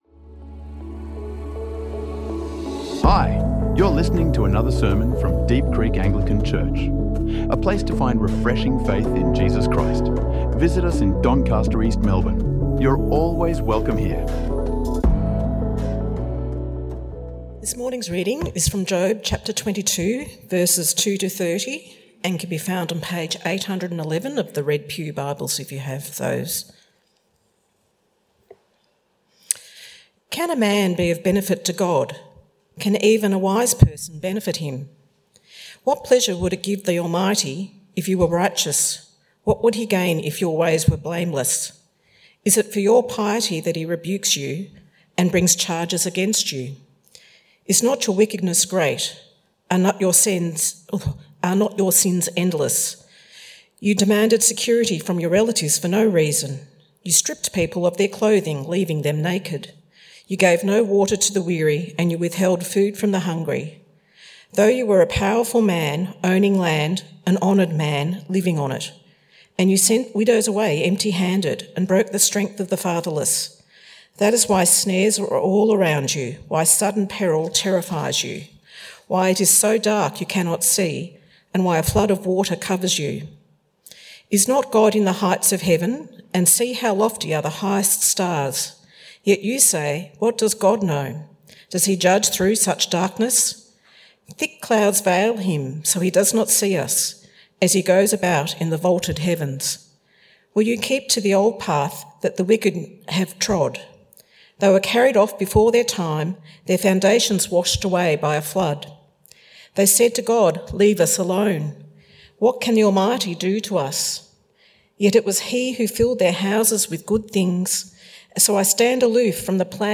In this sermon from our Job: The Mystery of Suffering series, we explore what happens when well-meaning words miss the mark.